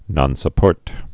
(nŏnsə-pôrt)